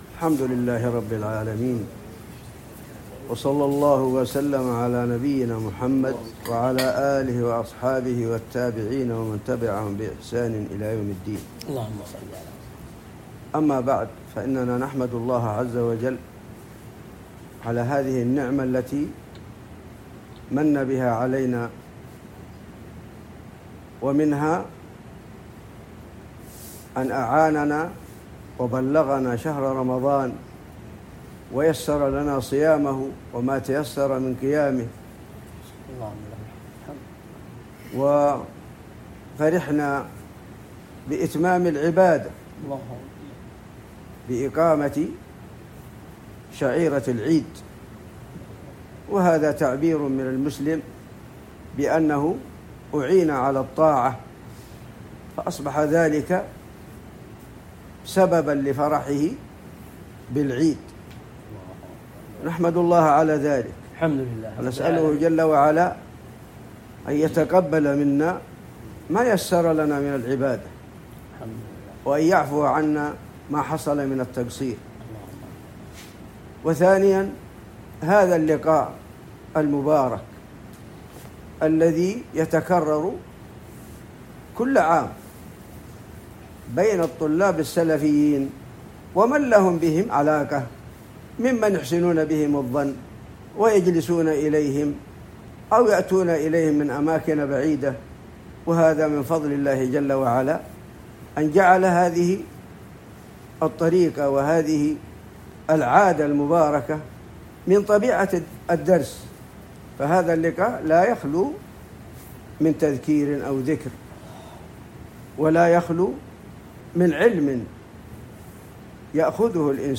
بعد عصر السبت ٧ شوال ١٤٤٦هـ بمدينة جازان